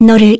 Scarlet-vox-get_03_kr.wav